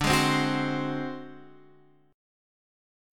D7b5 chord